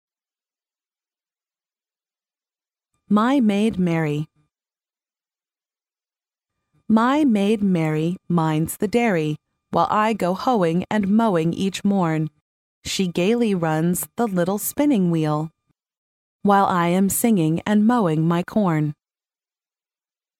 幼儿英语童谣朗读 第9期:我的女仆玛丽 听力文件下载—在线英语听力室